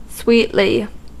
Ääntäminen
Ääntäminen : IPA: /zyːs/